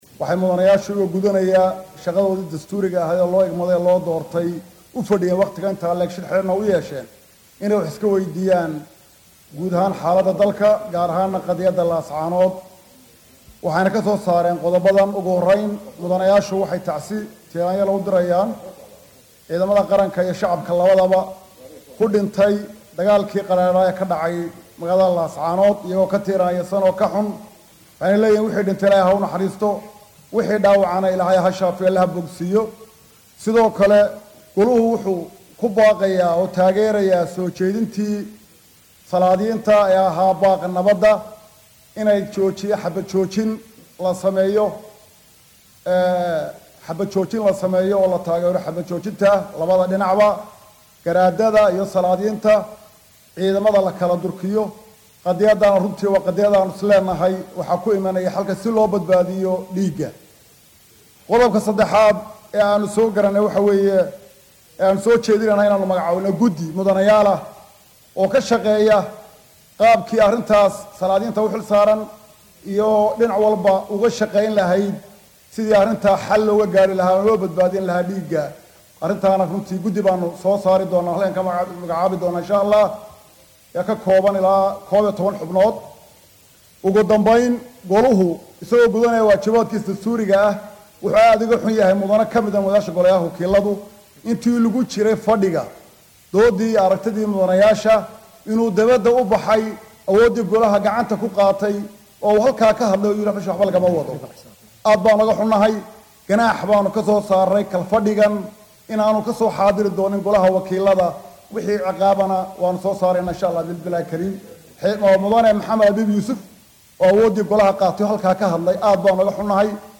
Waxay soo saareen qaraar ka kooban qodobbo dhowr ah oo ay ka mid tahay in labada dhinacba ay xabad joojin sameeyaan iyo in howshan guddi loo saaraya. Guddoomiye ku xigeenka golaha wakiillada Soomaaliland Saciid Mire Girre ayaa warbaahinta u akhriyay qaraarka ay soo saareen.